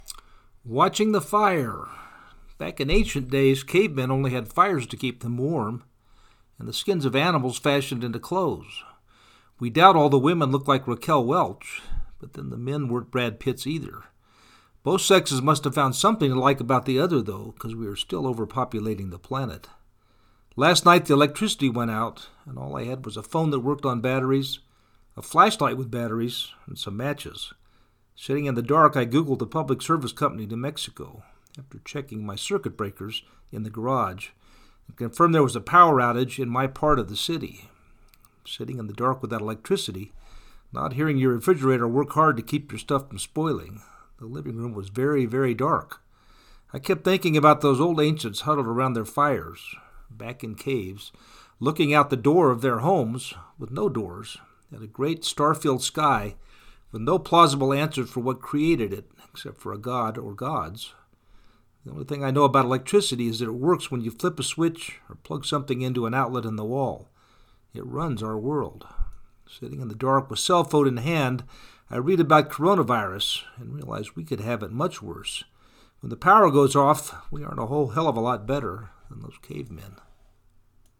Watching the Fire During a power outage
watching-the-fire.mp3